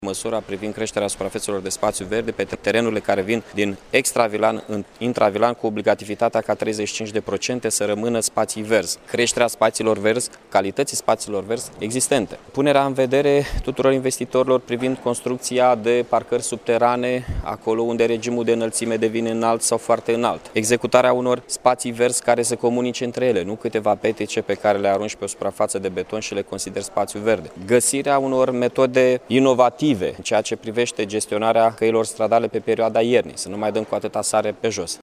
Planul Urbanistic General al municipiului Iaşi va trebui armonizat cu Strategia de dezvoltare a localităţii până în anul 2030, a afirmat, astăzi, primarul Mihai Chirica, în cadrul dezbaterii acestui document pe componenta de mediu.